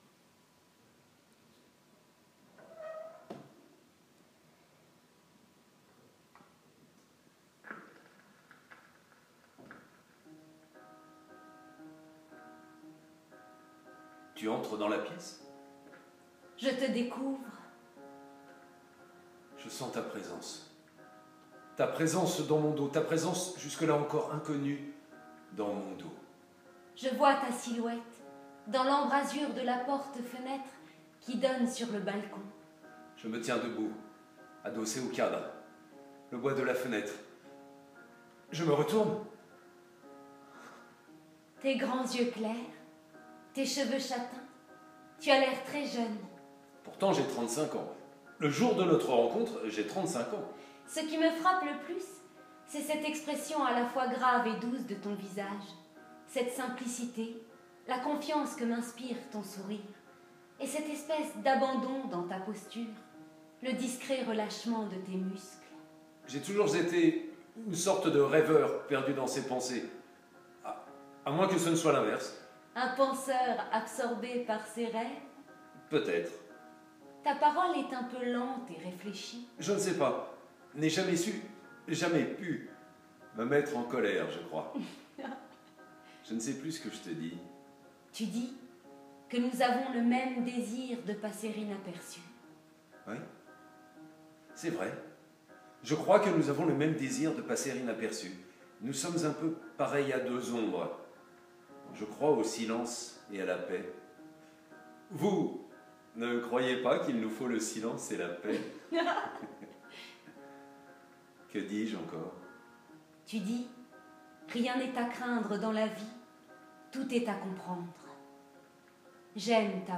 Extrait audio du spectacle